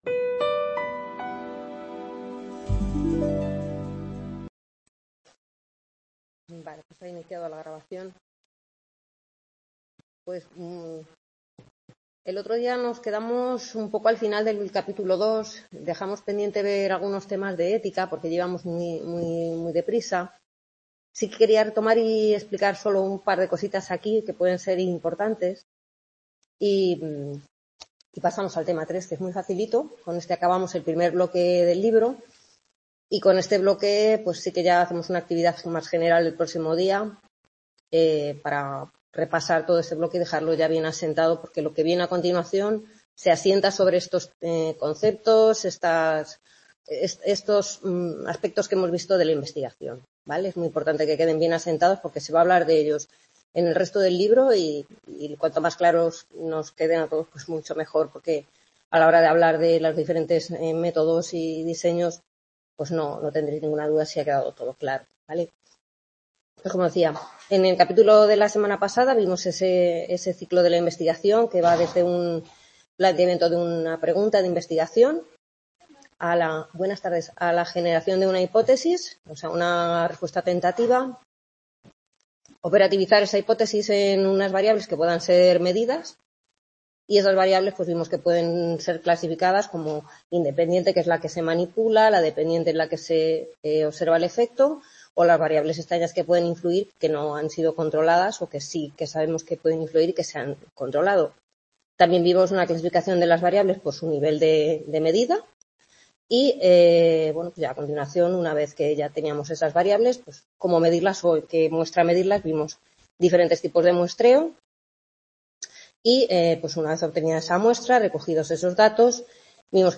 Tutoría 3 Fundamentos de investigación en Psicología - Validez de las conclusiones de la investigación